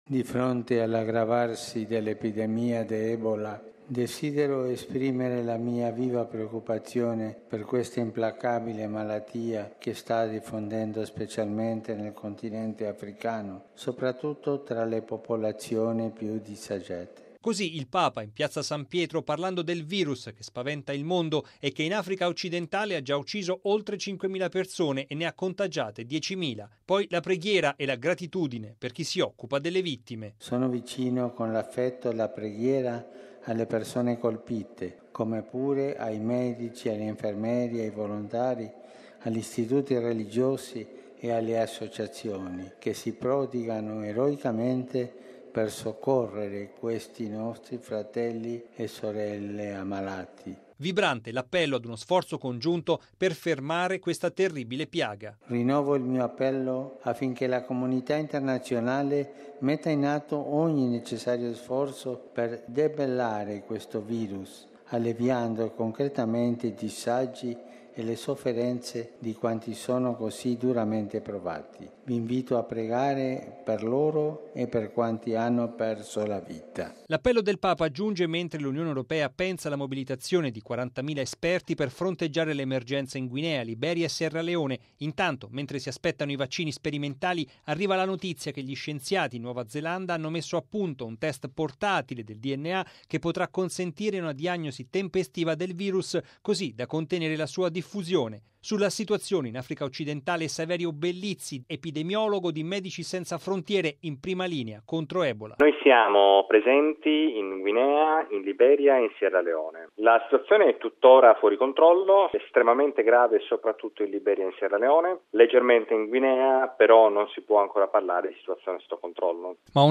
“Di fronte all’aggravarsi dell’epidemia di ebola”, il Papa chiede alla Comunità internazionale di mettere in “atto ogni sforzo per debellare il virus”. Nell’appello levato al termine dell’udienza generale, in Piazza San Pietro, Francesco si è detto vicino con “l’affetto e la preghiera” alle persone colpite dalla pandemia e a quanti operano per sconfiggerla e aiutano i contagiati.